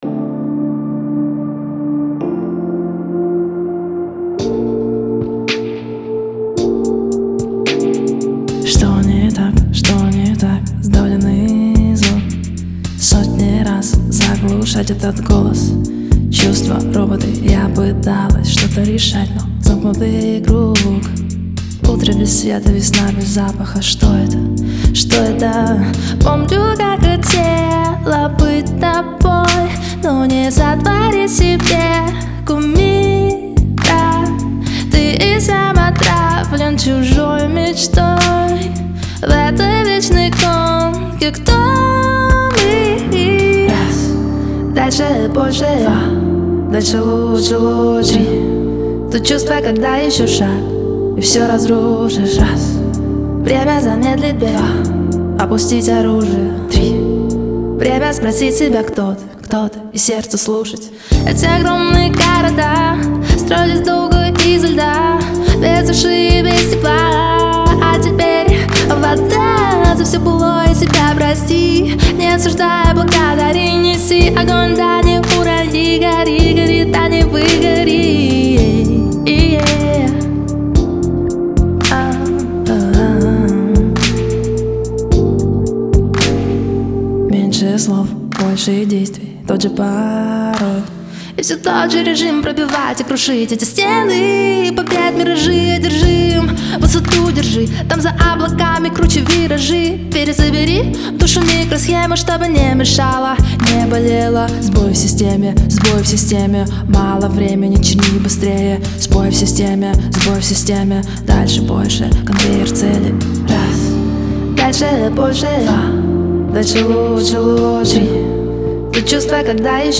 яркая и эмоциональная песня
в жанре поп с элементами электронной музыки